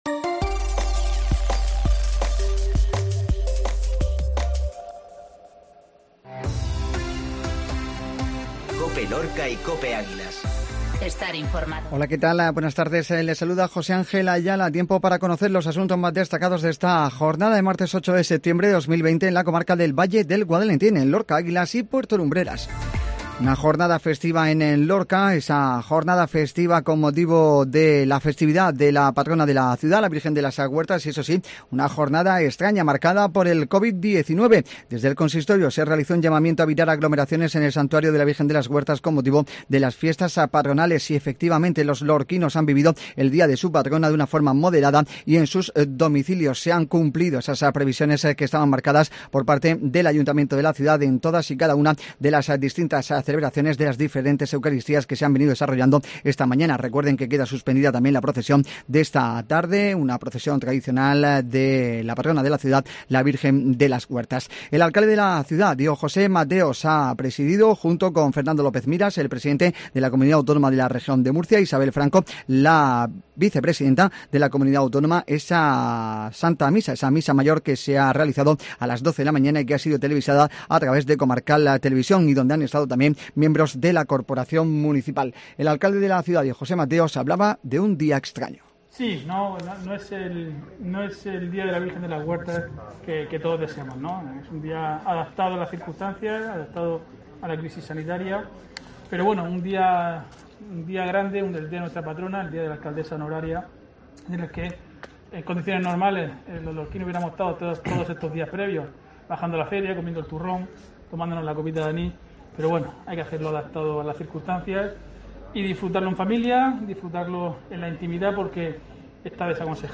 INFORMATIVO MEDIODÍA COPE 0809